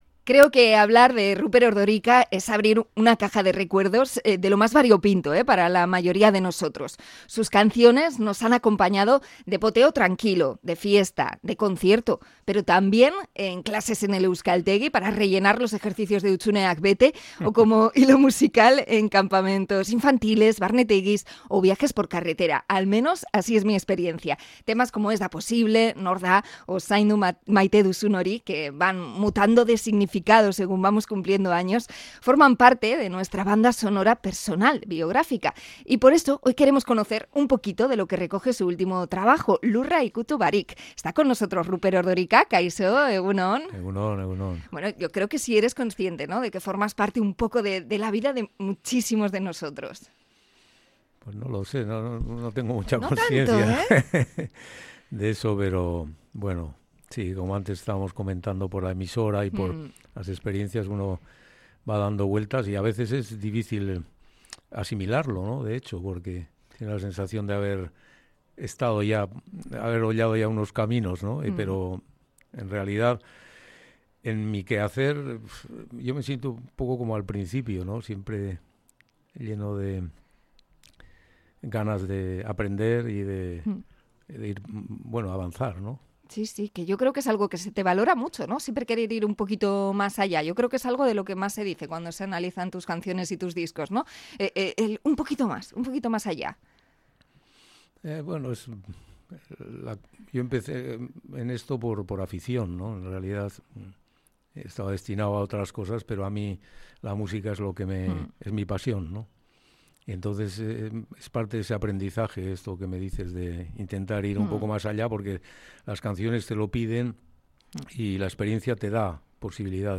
Entrevista con Ruper Ordorika por su nuevo disco Lurra Ikutu Barik